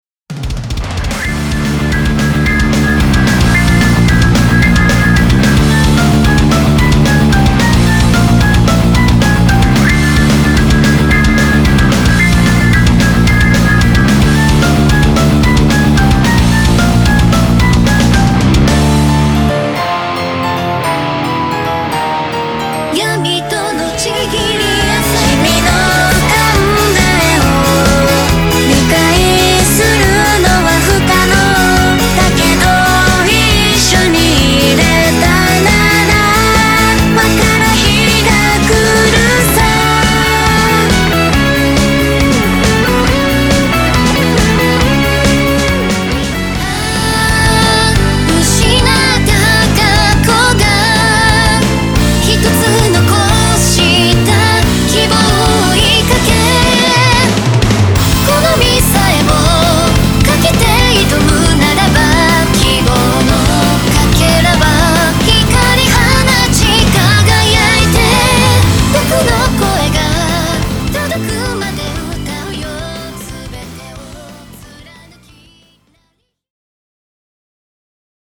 EUROBEAT
クロスフェードmp3 　XFD mp3